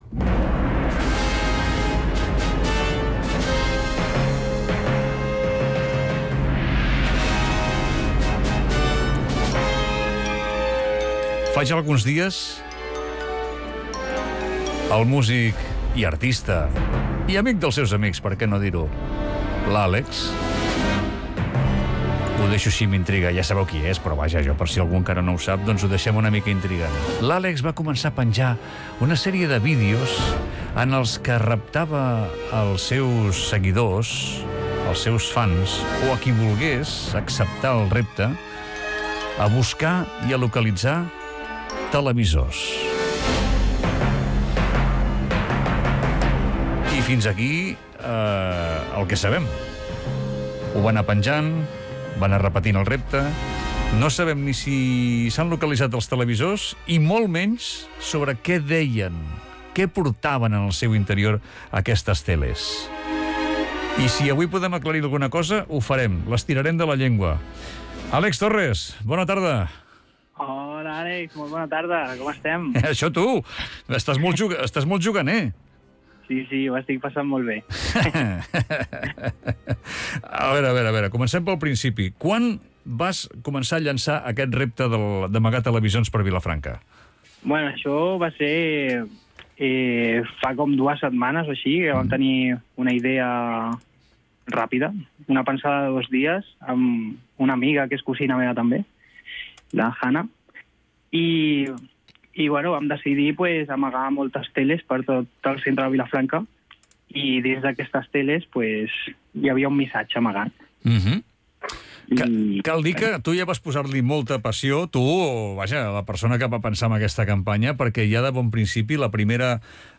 Radiofórmula
Entrevista als Grass is greener 3/7/25